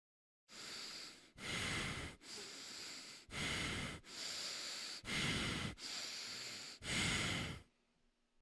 Heavy Breathing Sound Effect Free Download
Heavy Breathing